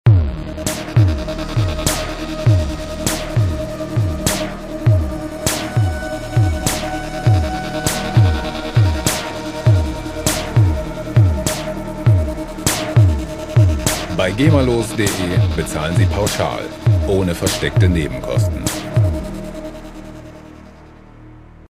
epische Musikloops lizenzfrei
Musikstil: Electroclash
Tempo: 100 bpm